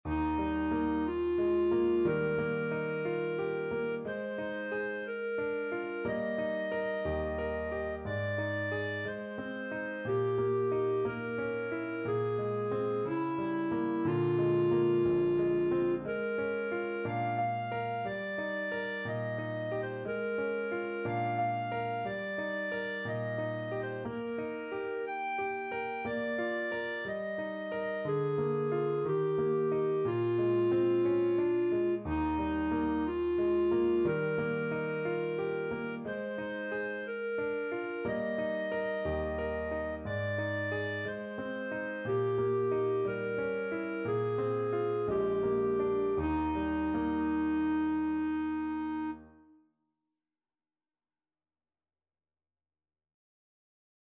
Classical Granados, Enrique Dedicatoria (from Cuentos de la Juventud, Op.1) Clarinet version
~ = 60 Andantino (View more music marked Andantino)
Eb major (Sounding Pitch) F major (Clarinet in Bb) (View more Eb major Music for Clarinet )
2/4 (View more 2/4 Music)
Classical (View more Classical Clarinet Music)